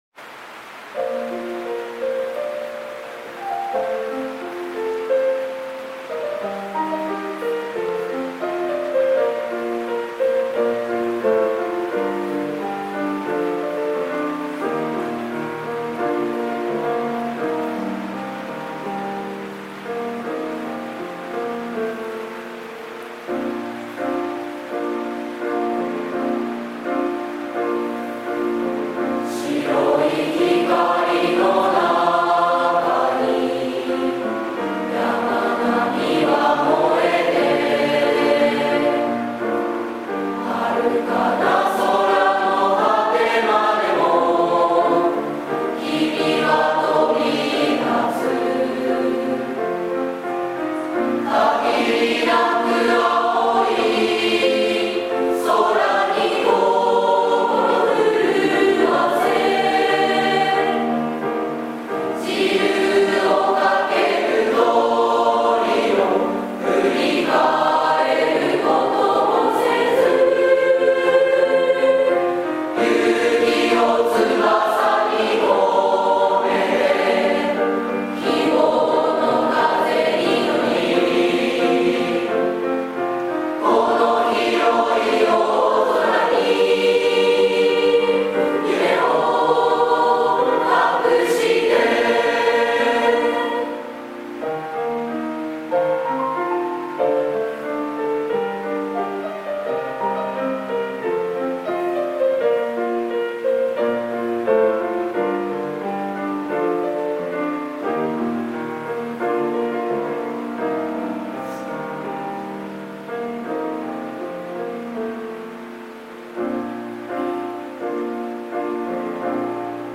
厳粛な式典「第55回卒業証書授与式」の主役を 務めあげた55期生は、胸張り花道を歩み、 「きせき」の空間である体育館を後にしました。 55期生「旅立ちの日に」